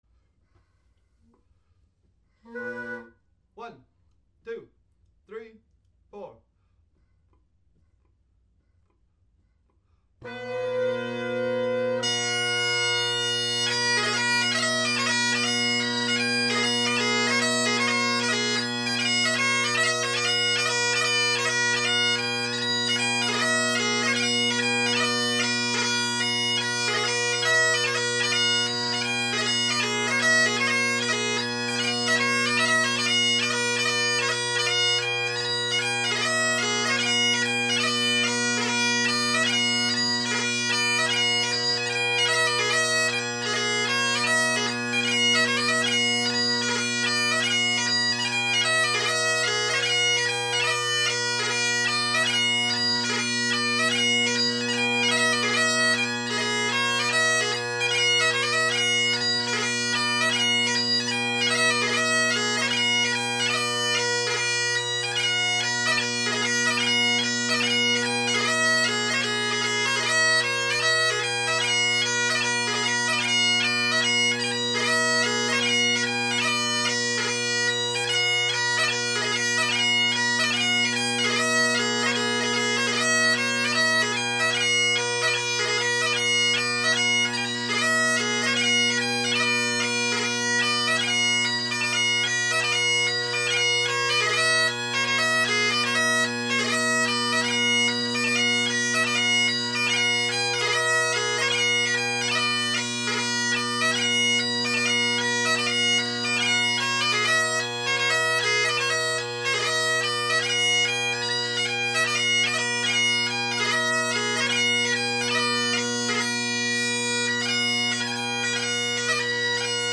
Great Highland Bagpipe Solo, Reviews